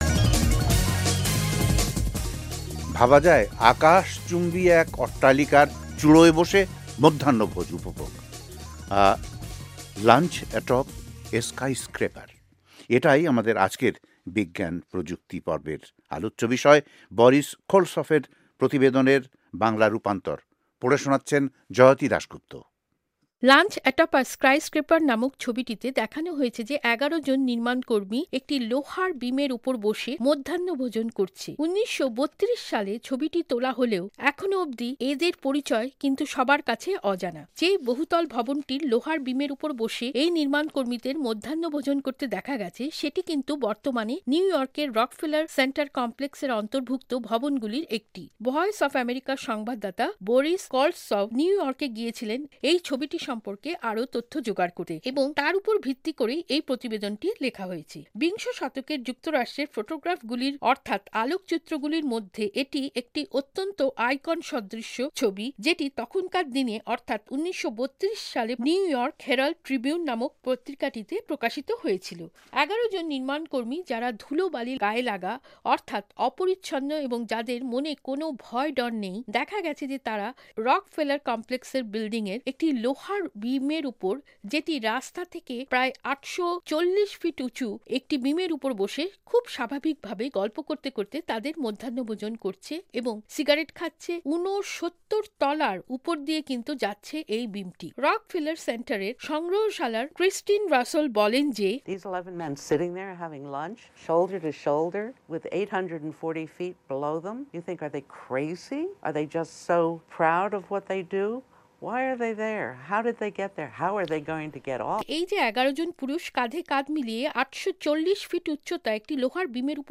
বিজ্ঞান ও প্রযুক্তি পর্বে বাংলা রুপান্তর পড়ে শোনাচ্ছেন